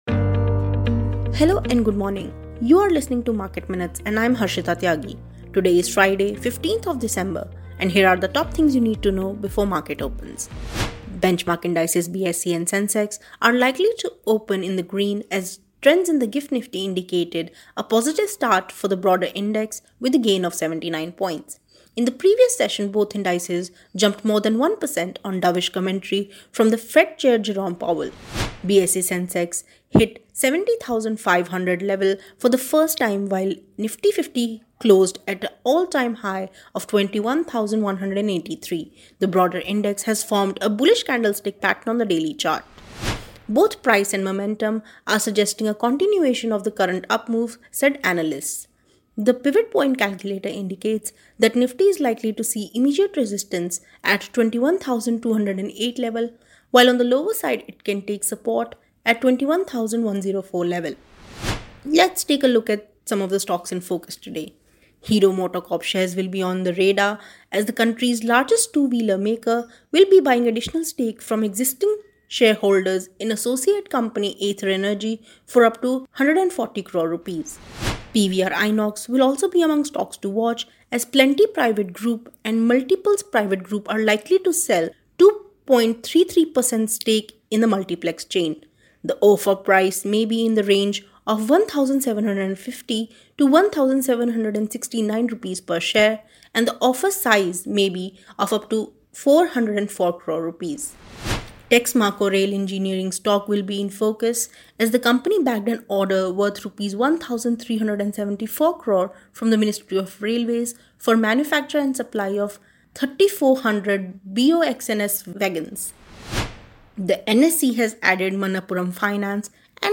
Market Minutes is a morning podcast that puts the spotlight on hot stocks, key data points, and developing trends.